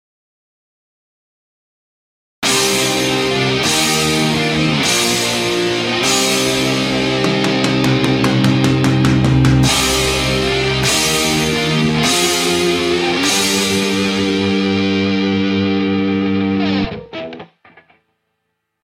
Вниз  Играем на гитаре
METAL.mp3